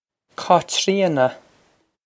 Cáitríona Kah-chree-uh-nuh
This is an approximate phonetic pronunciation of the phrase.